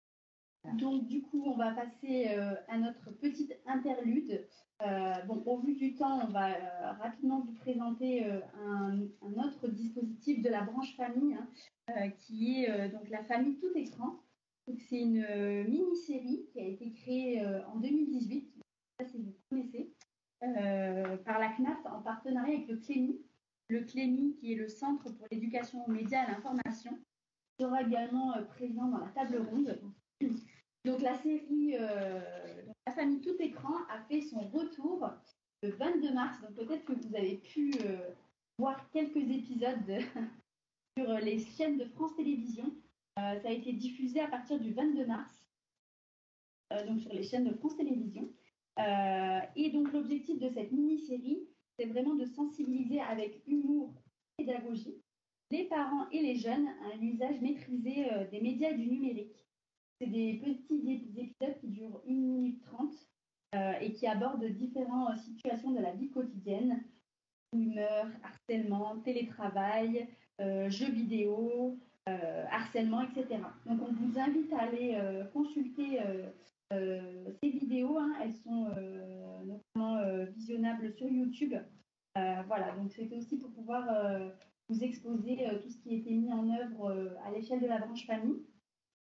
Enregistrement visioconférence.